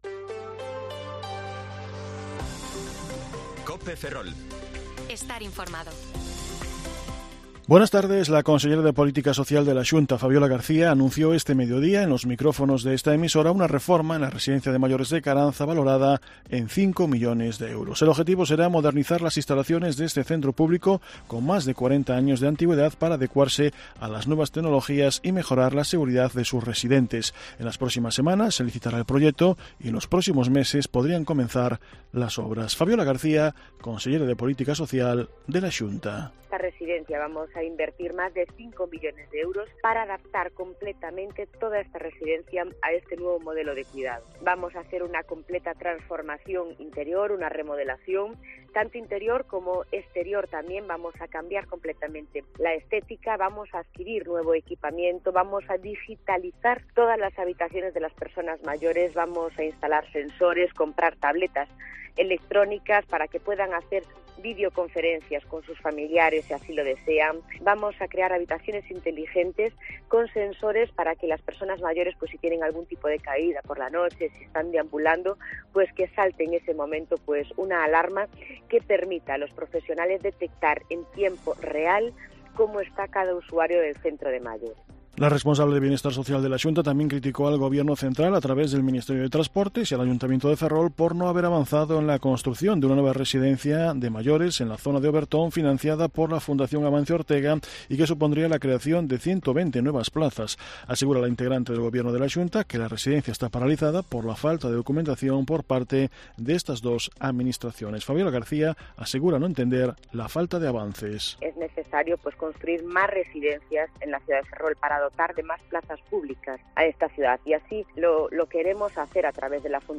Informativo Mediodía COPE Ferrol 14/4/2023 (De 14,20 a 14,30 horas)